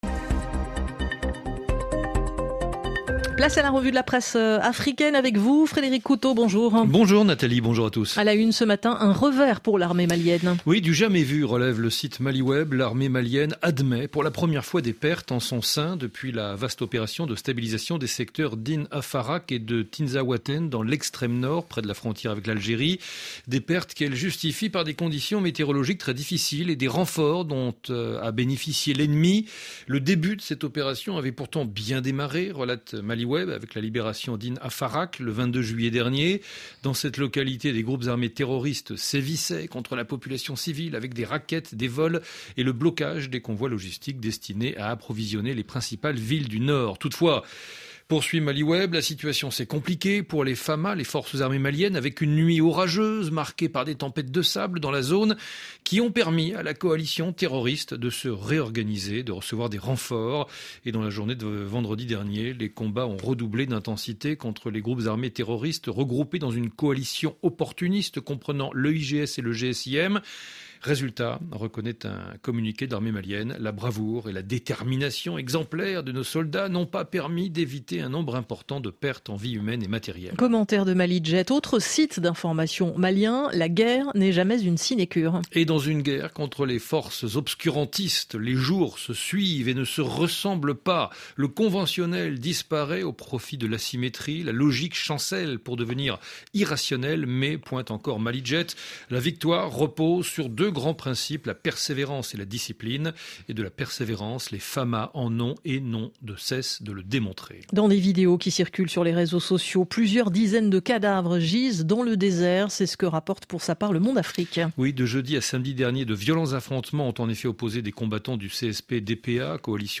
Tranche d'information afrique 02/01 04h42 GMT - 02.01.2024